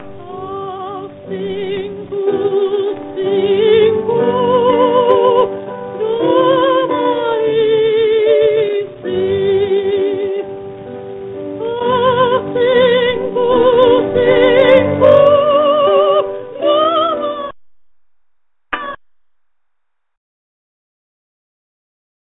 Like his mother Charlotte Garrigue, Jan Masaryk was a talented piano player, and he adapted folk songs for the piano, including this one, which was also his father's favourite, Ach, synku, synku, Oh, my son: